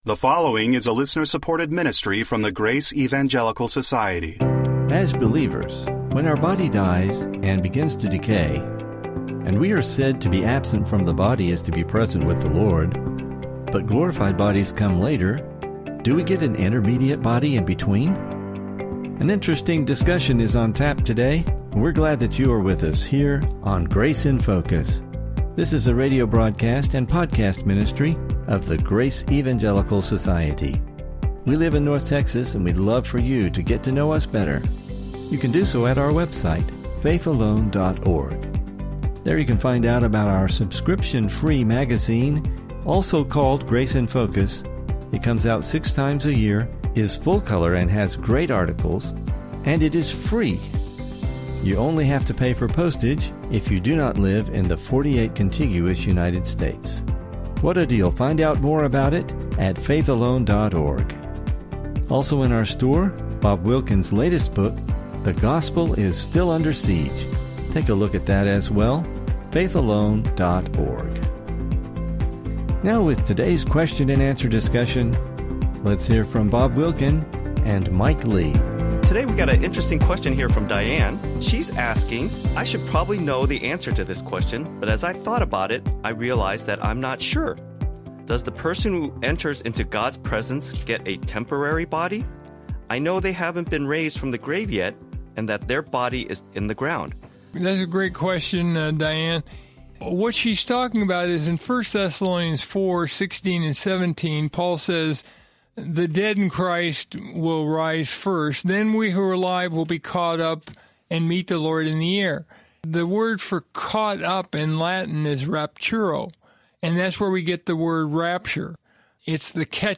Until he or she receives a glorified body (yet in the presence of the Lord), will there be some sort of intermediate body? This will be an interesting discussion.